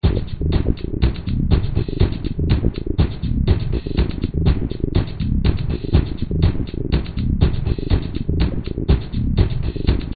描述：用ReBirth中的Regulator Mod制作的声音。
Tag: 节拍 重生